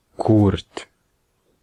Ääntäminen
US : IPA : [ɪn.ˈsɑɪt]